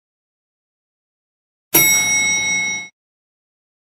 دانلود صدای زنگ آسانسور 3 از ساعد نیوز با لینک مستقیم و کیفیت بالا
جلوه های صوتی